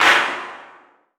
CDK Loud Clap.wav